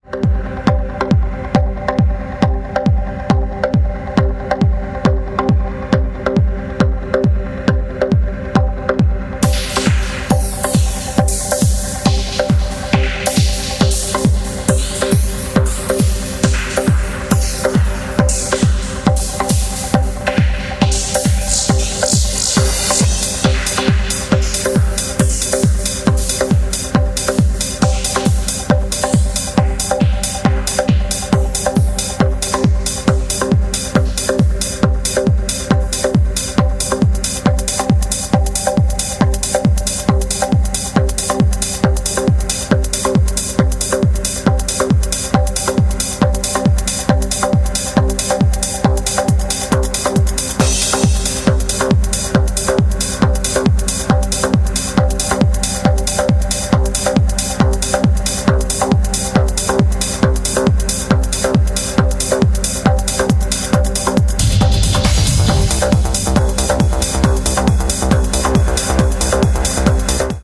Транс , Хаус , Прогресив
Електроніка